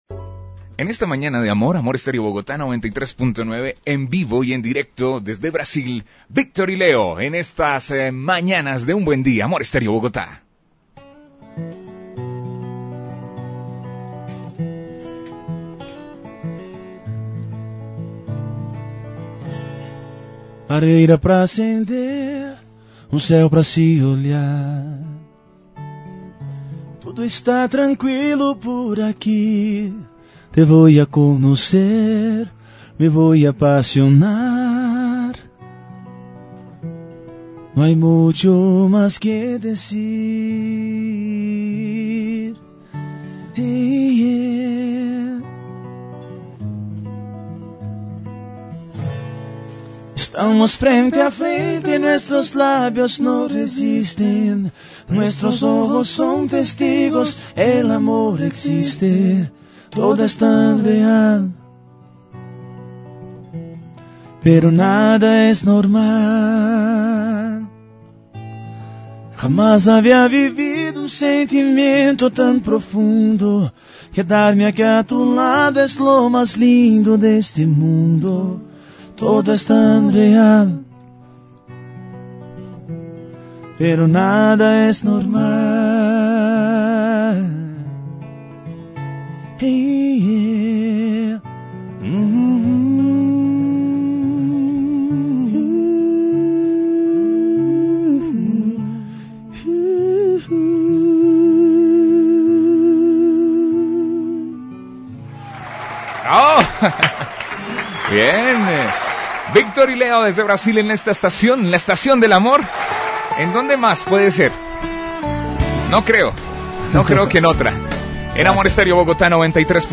Entrevista en vivo con Victor y Leo Confira a entrevista de Victor & Leo para a rádio Amor Stereo da Colômbia.